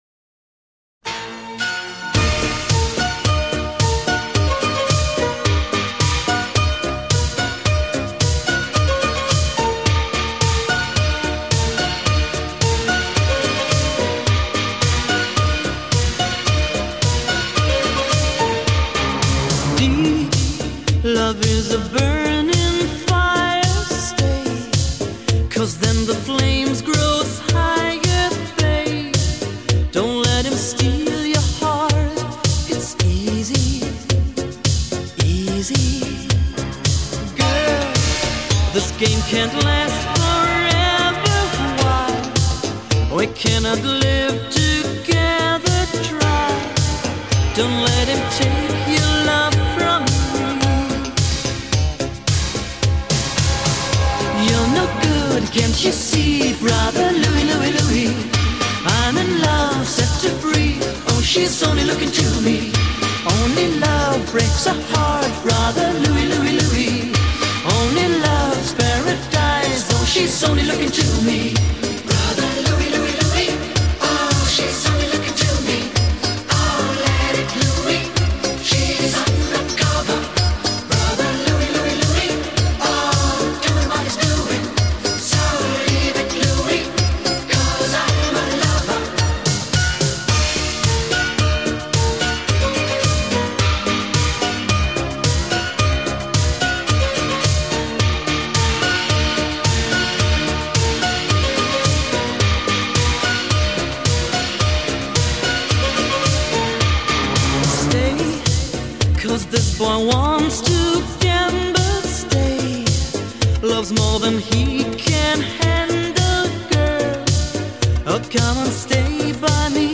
锐不可当的欧式舞曲